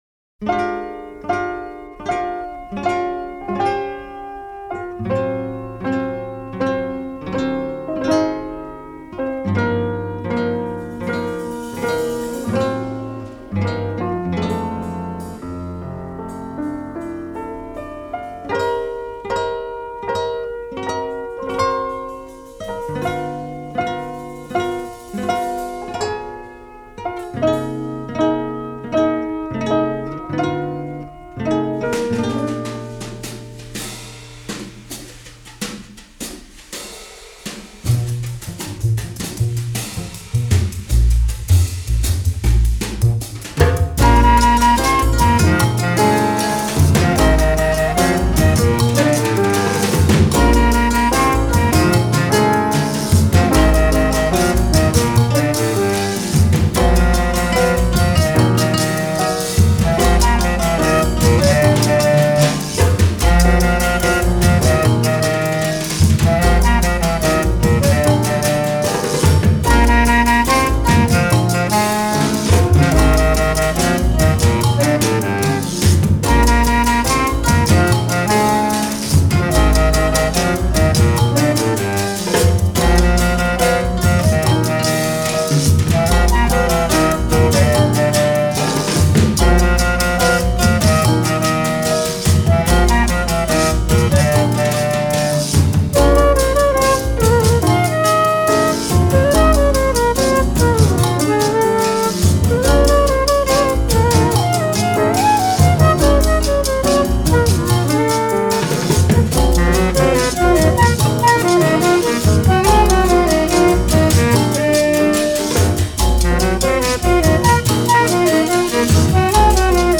unknown piano player